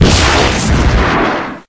missileTakeOffAlt.ogg